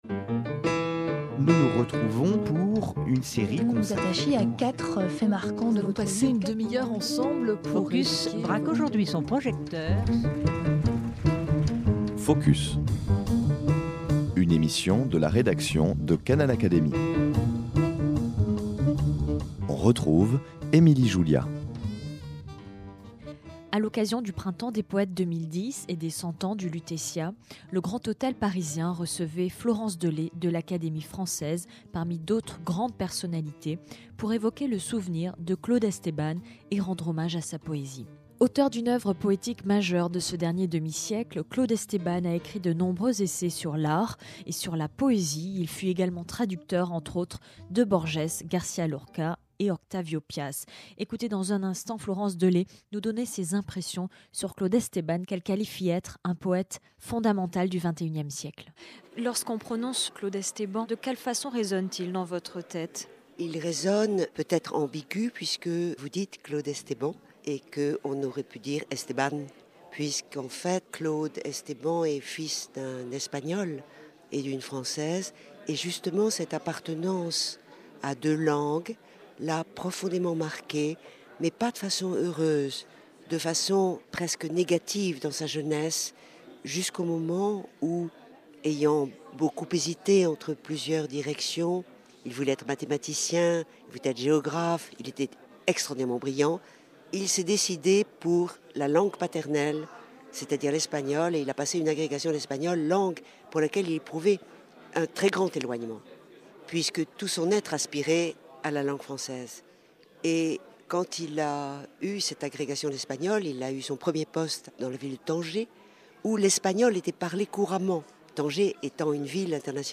Le Printemps des poètes 2010 a fourni à Florence Delay l'occasion d'évoquer Esteban, à l'Hôtel Lutetia qui fêtait en 2010 ses cent ans. C'est là que nous l'avons interviewée.